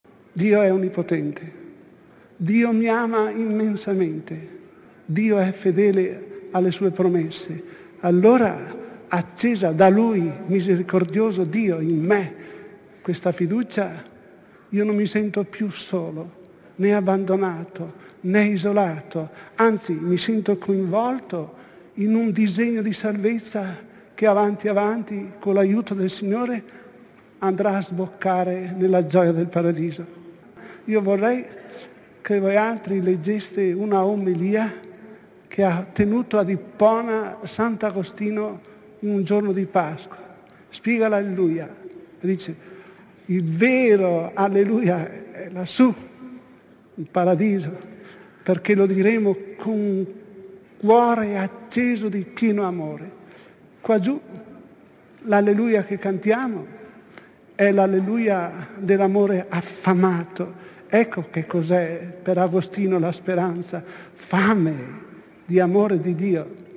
Attingendo all’archivio sonoro della Radio Vaticana ripercorriamo alcune riflessioni dei Pontefici sui tre possibili destini per l’anima
Papa Giovanni Paolo I lo ricorda all’udienza generale del 20 settembre 1978: